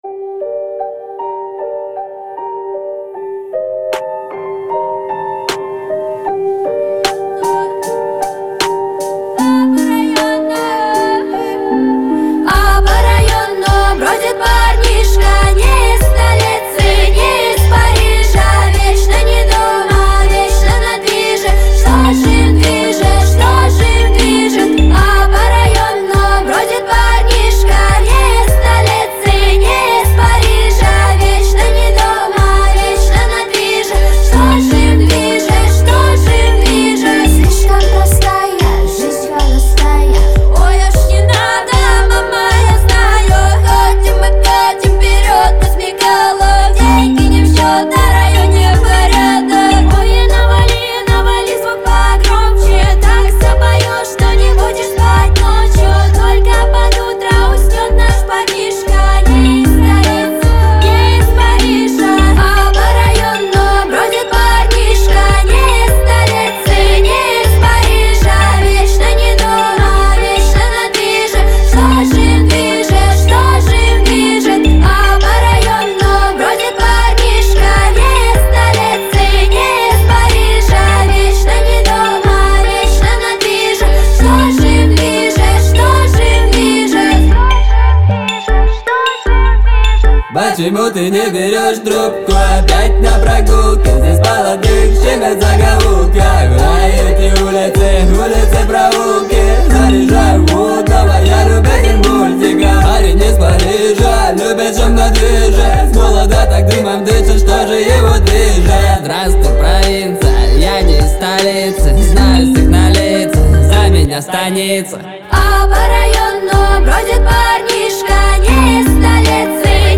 это энергичная и ритмичная композиция в жанре хип-хоп